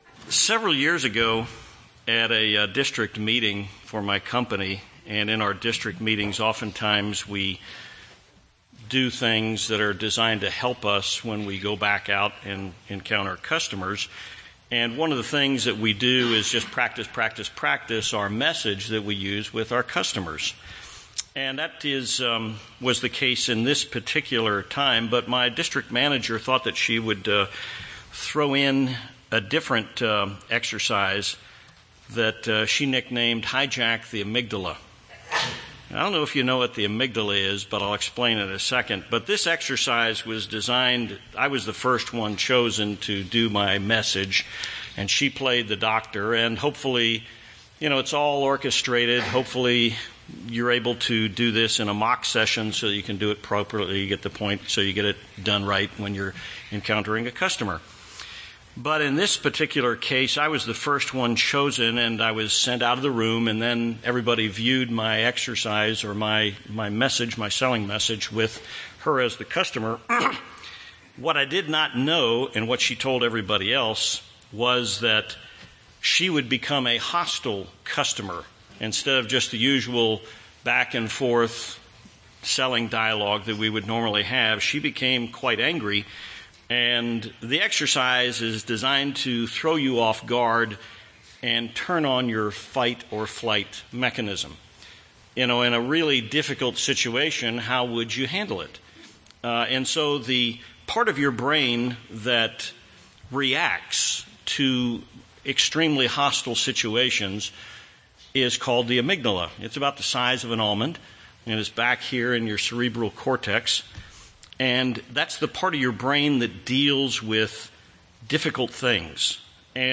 Sermons
Given in Tucson, AZ